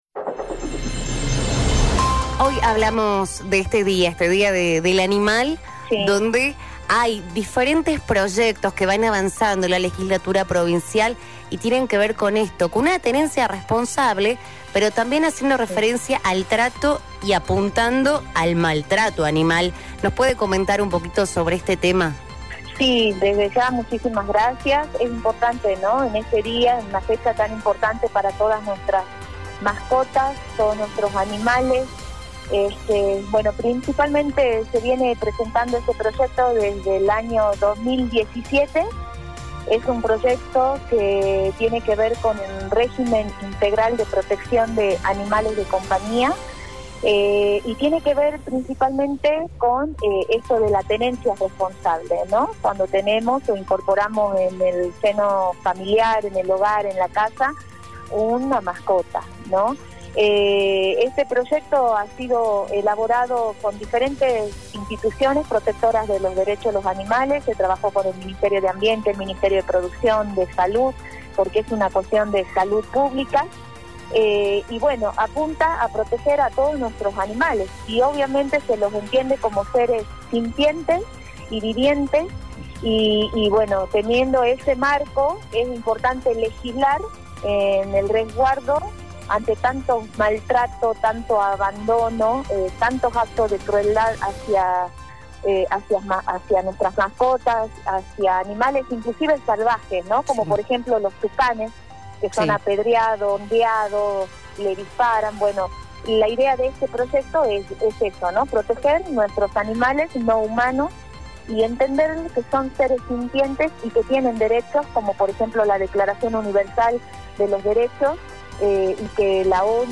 Victoria luna murillo – diputada provincial.mp3
La diputada Victoria Luna Murillo, en el día del animal comentaba en La Mañana de City, sobre las bases de este proyecto, que desde 2017 está siendo yratado, sien embargo no contó con el compromiso de toda la legislatura para su aprobación.